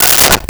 Plastic Plate 03
Plastic Plate 03.wav